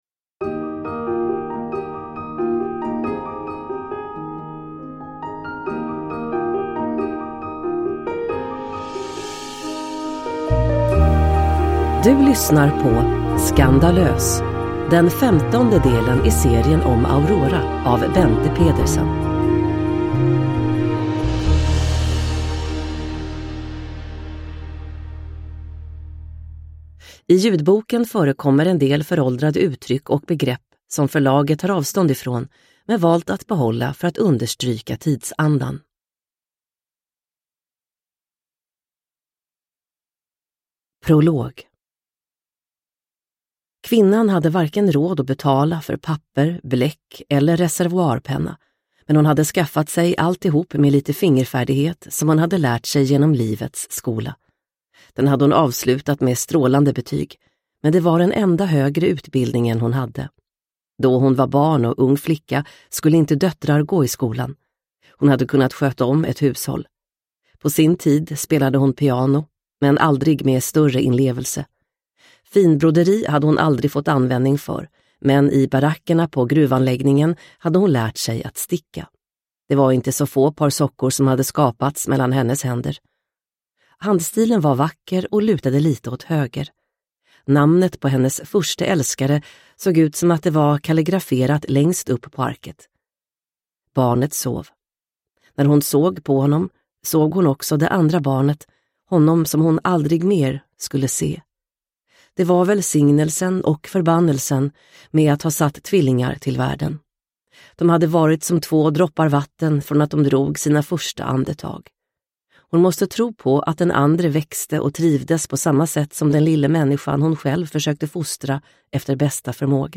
Skandalös – Ljudbok – Laddas ner